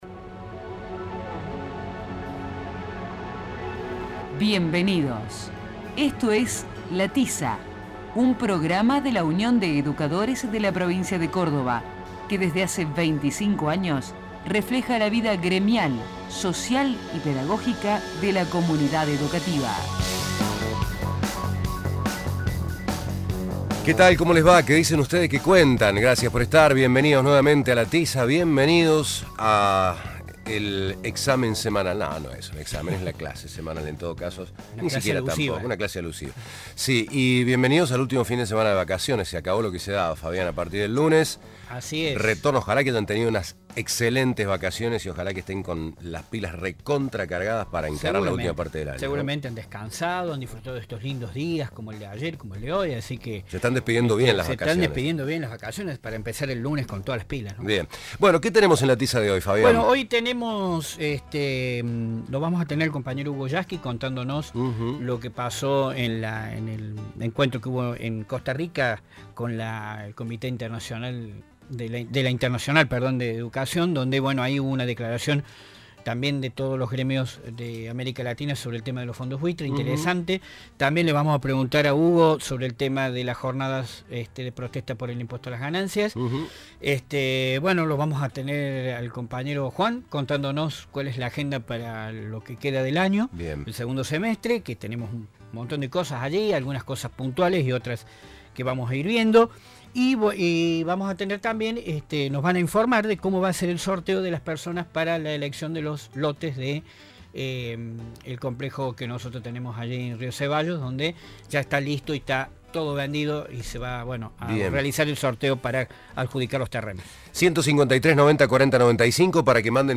El Sindicato cuenta con el programa de radio "La Tiza", en el cual se reflejan las temáticas gremiales, educativas y pedagógicas que interesan a los y las docentes.
El programa se emite los sábados de 12 a 13 hs por radio Universidad (AM 580) y de 16 a 17 hs por Más que música (FM 102.3).